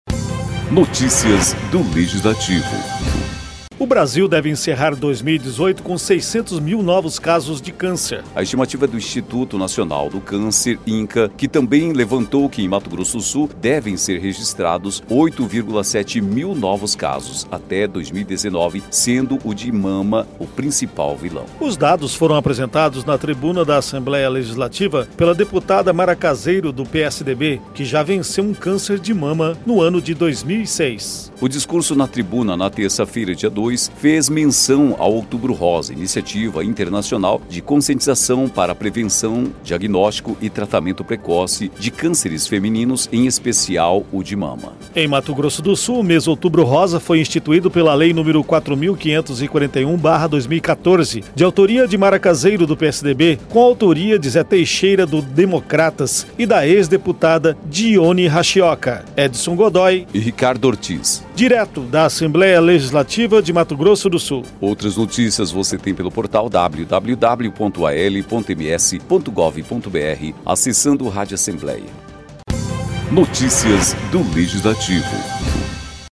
Os dados foram apresentados na tribuna da Assembleia Legislativa pela deputada Mara Caseiro (PSDB), que já venceu um câncer de mama em 2006.